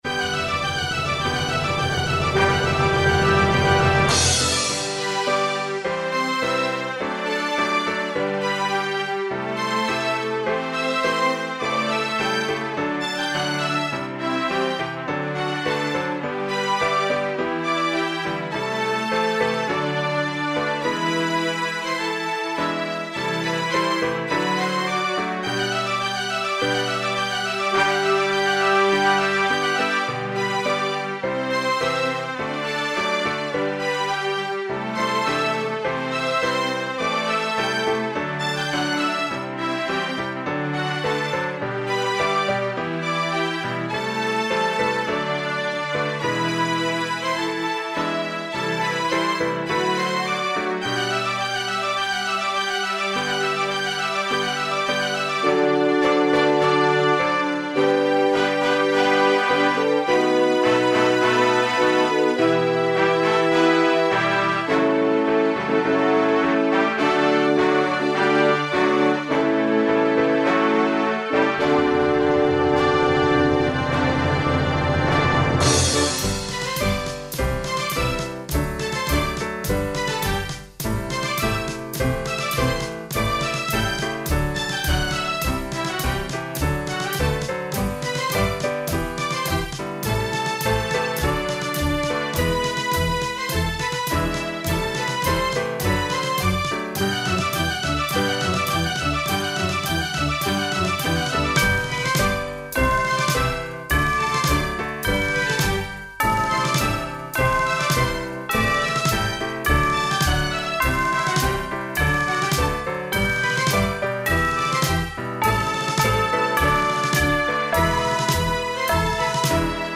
accompaniment tracks  for
full orchestra with piano